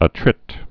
(ə-trĭt) or at·trite(ə-trĭt)